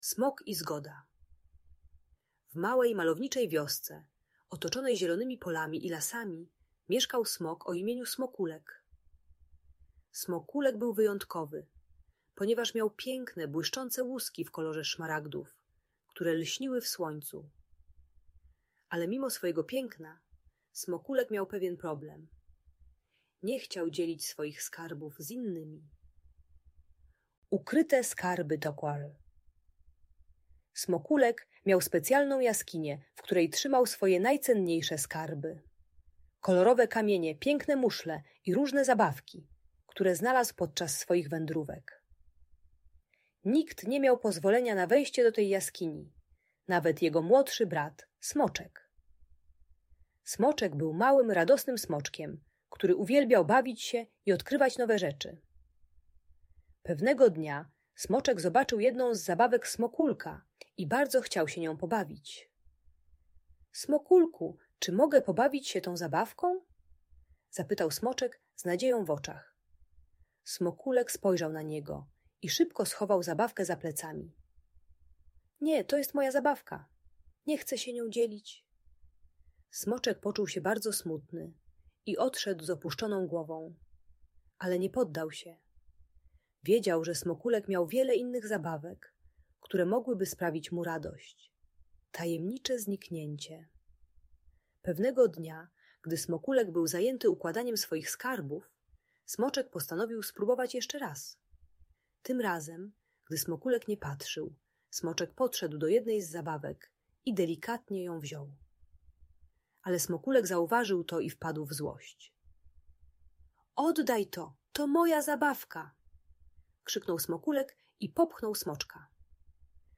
Historia o Smoku i Zgodzie - Audiobajka